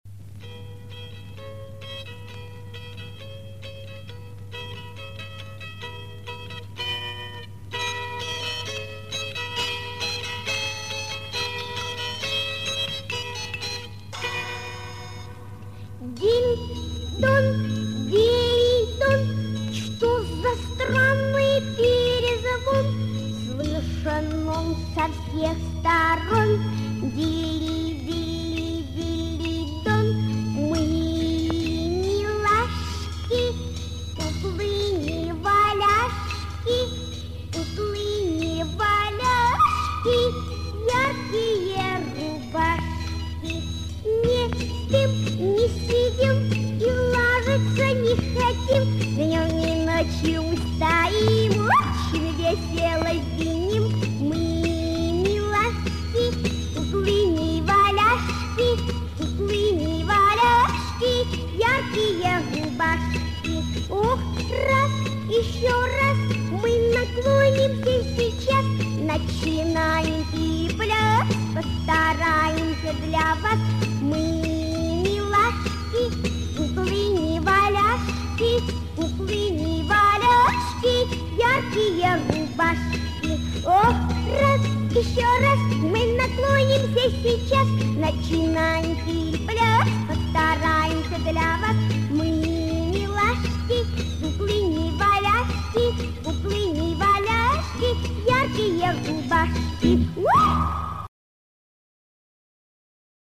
песня с движениями
песенки для 2-3 летдля малышей 0+